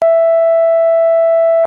Dzmm Beep Sound - Sound Effect Button